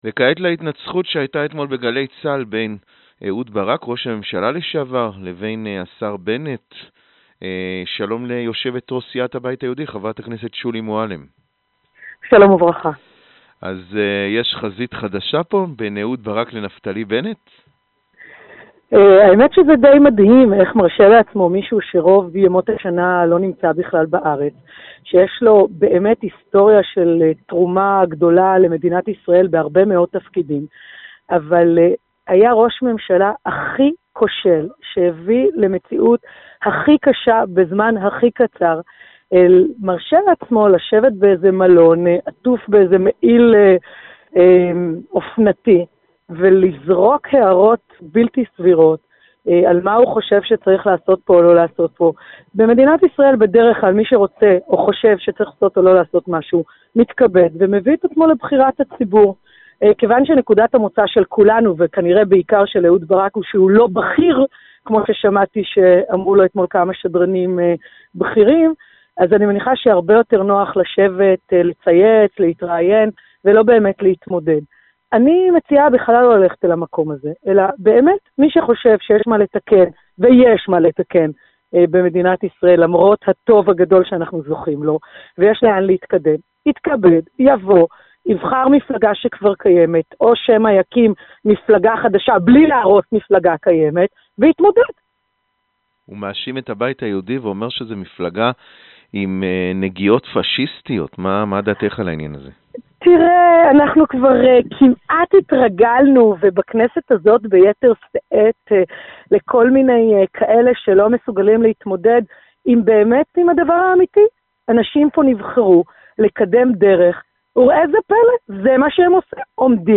Полностью интервью с Шули Муалем-Рафаэли можно прослушать, перейдя по этой ссылке.